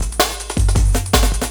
06 LOOP08 -R.wav